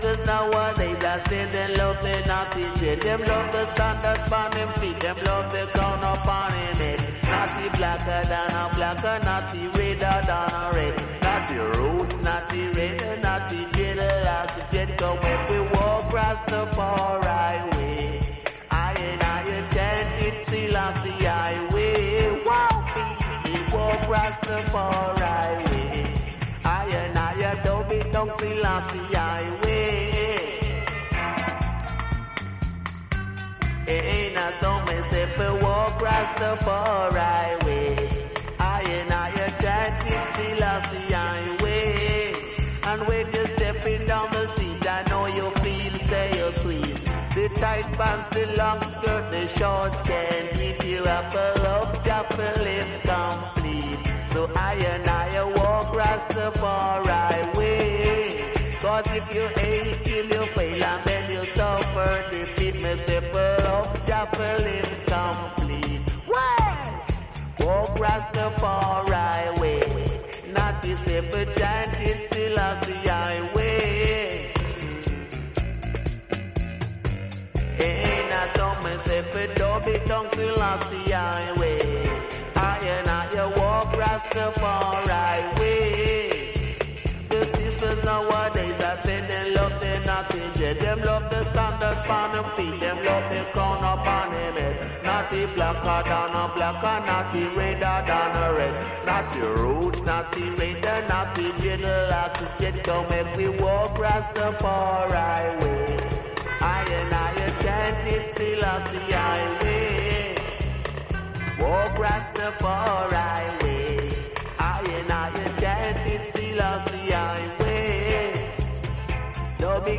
Dub Reggae